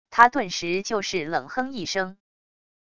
他顿时就是冷哼一声wav音频生成系统WAV Audio Player